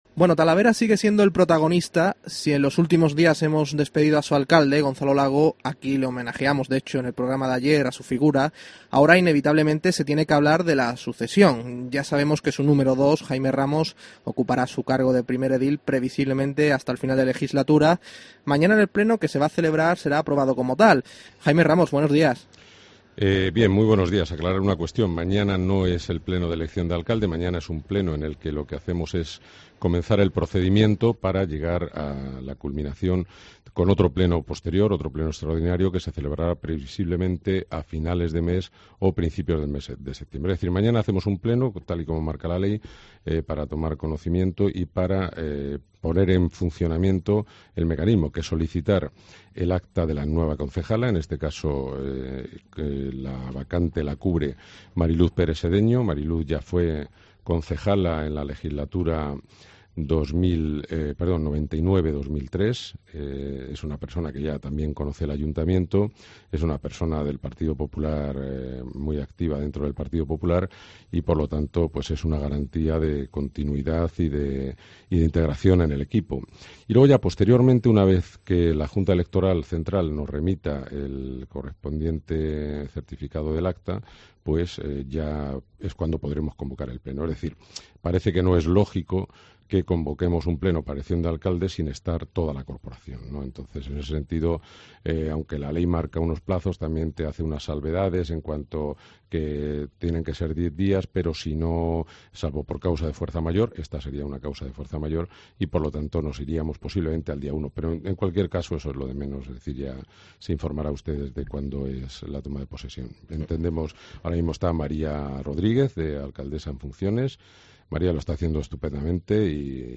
ENTREVISTA AL PRÓXIMO ALCALDE DE TALAVERA
AUDIO: COPE entrevista a Jaime Ramos, en unos días, nuevo alcalde de Talavera de la Reina.